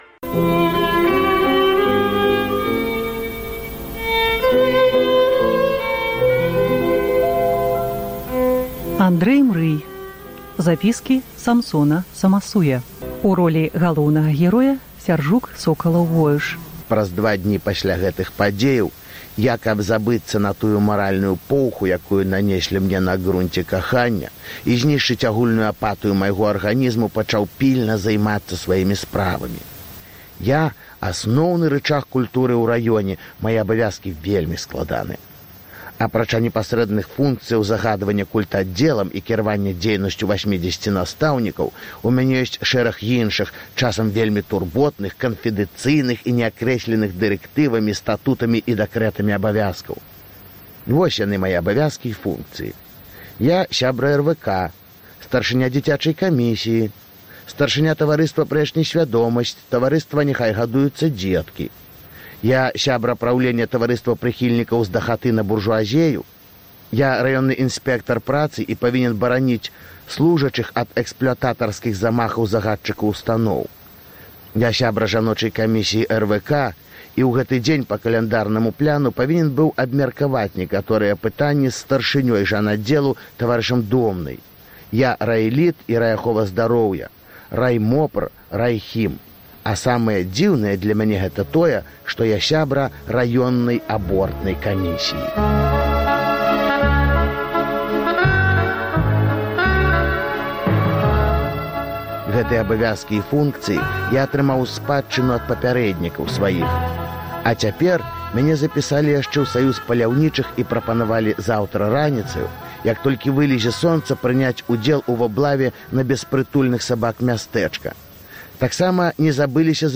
Чытае Сяржук Сокалаў-Воюш.